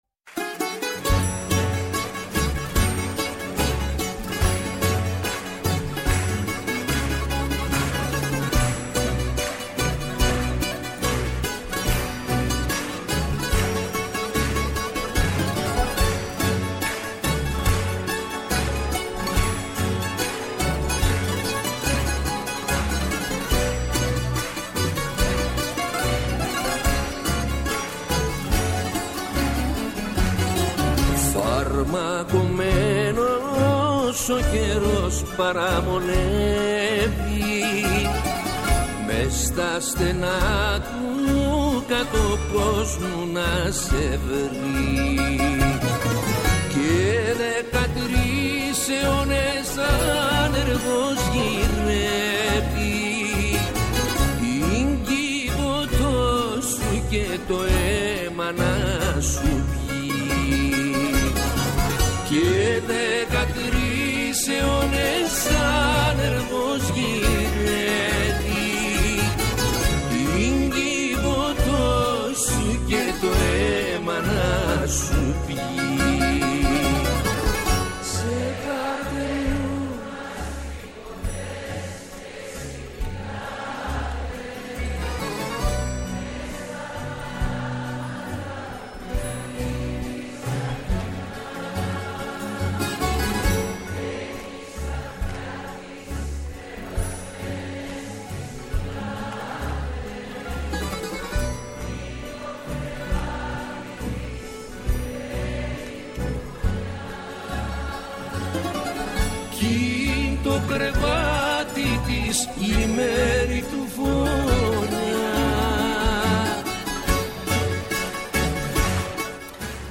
Καλεσμένος απόψε στο στούντιο